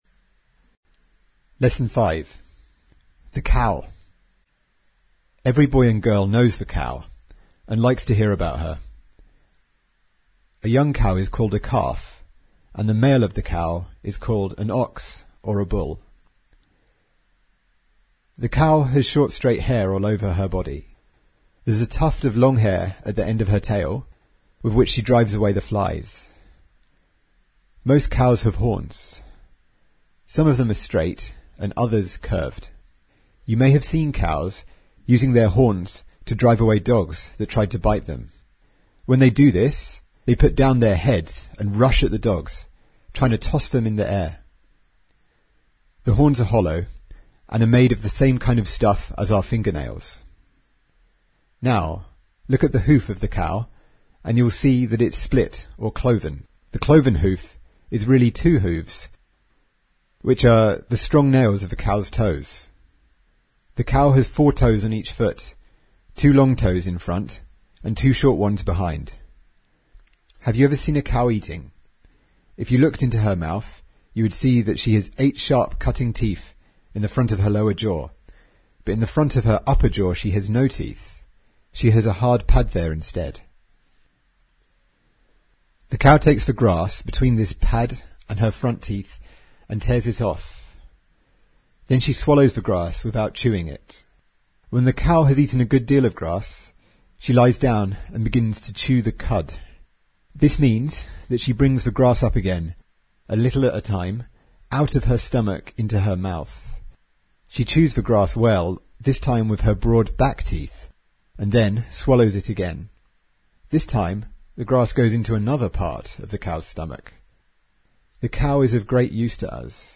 在线英语听力室英国学生科学读本 第5期:牛的听力文件下载,《英国学生科学读本》讲述大自然中的动物、植物等广博的科学知识，犹如一部万物简史。在线英语听力室提供配套英文朗读与双语字幕，帮助读者全面提升英语阅读水平。